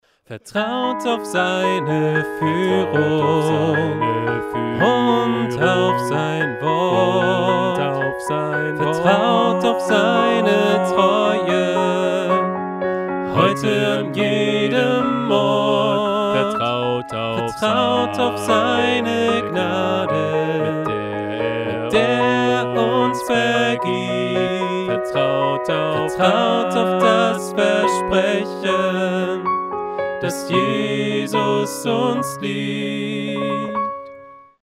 Notation: SATB
Tonart: A, G#m, +
Taktart: 4/4
Tempo: 120 bpm
Parts: 2 Verse, Refrain, Bridge
Noten, Noten (Chorsatz)